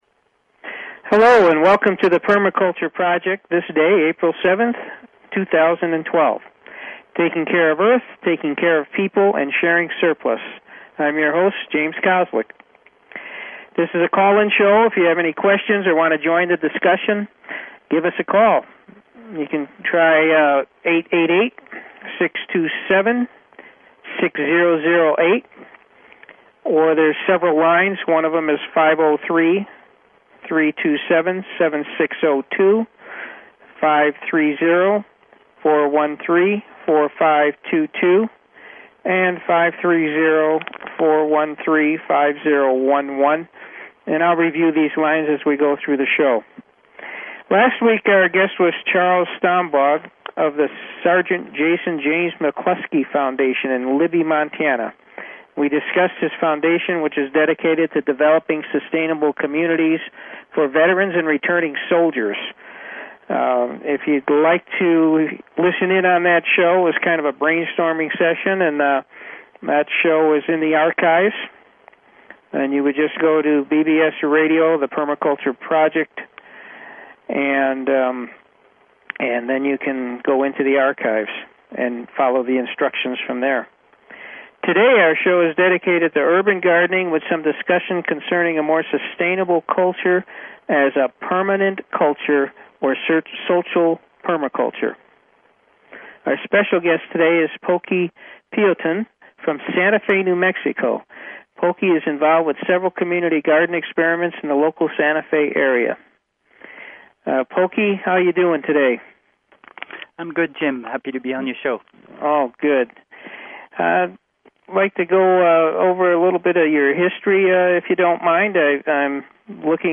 Talk Show Episode, Audio Podcast, Permaculture_Project and Courtesy of BBS Radio on , show guests , about , categorized as